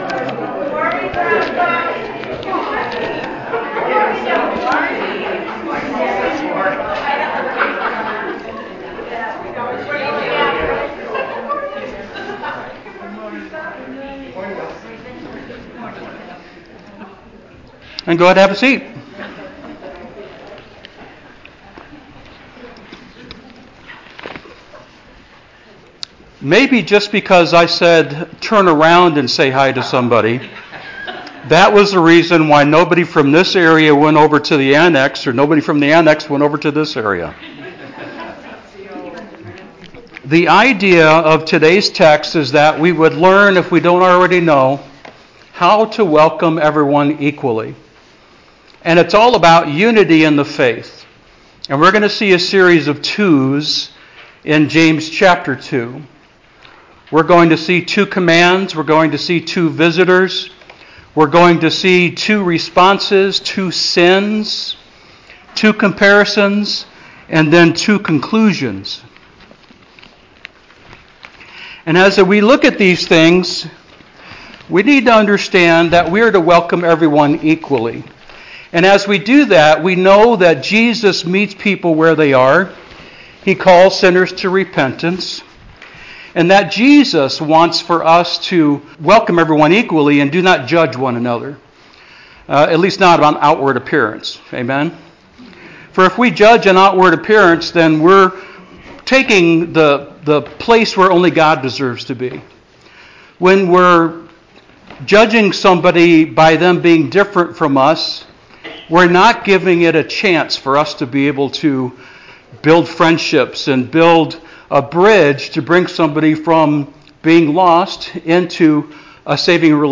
Sermon Outline: Two Commands (v. 1) Show no partiality.